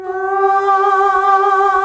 AAAAH   G.wav